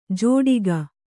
♪ jōḍiga